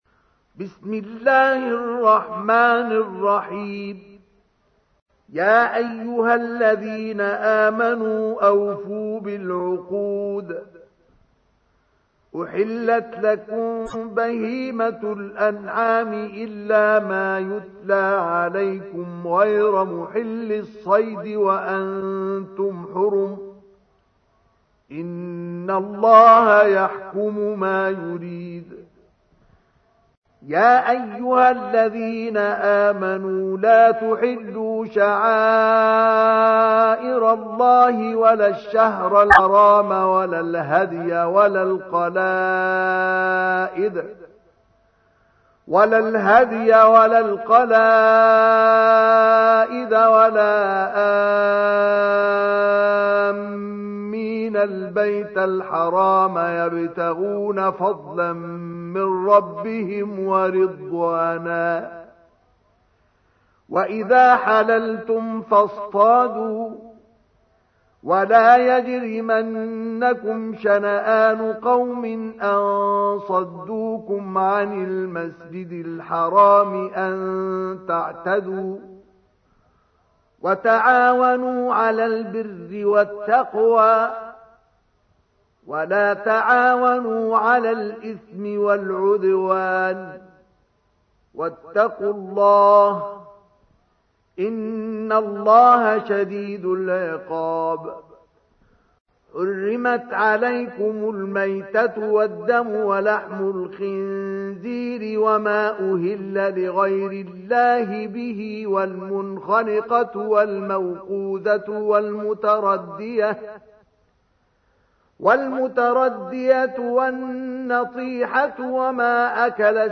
تحميل : 5. سورة المائدة / القارئ مصطفى اسماعيل / القرآن الكريم / موقع يا حسين